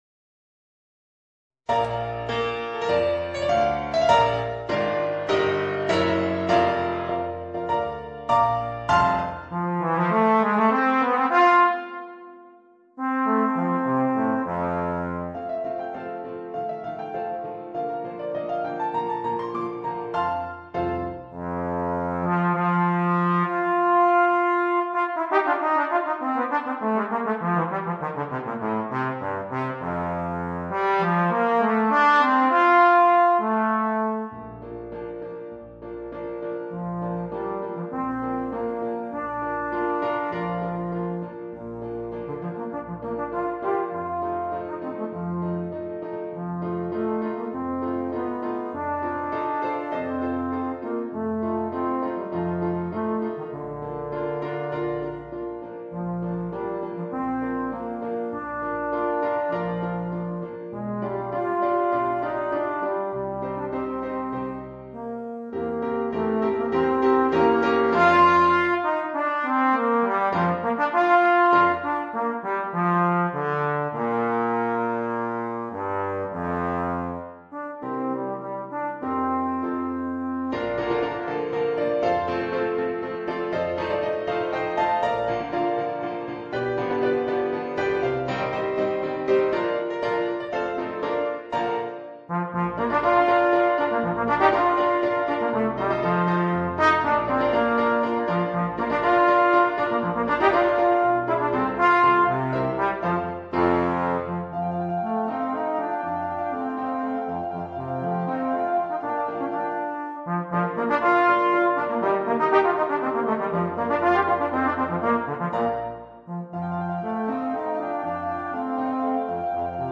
Voicing: Trombone and Piano or CD